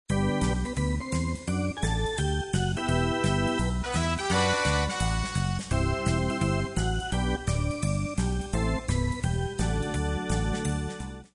Swing Demo Edit (Format:mp3,56kBit/s,24kHz,Stereo Size:78kB)